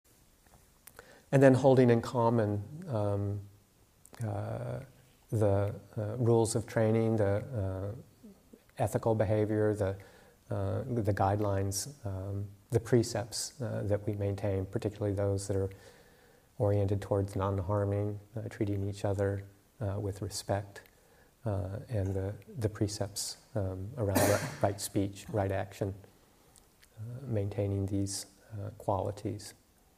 Principle of Cordiality #5: Ethical behavior and respect. Teaching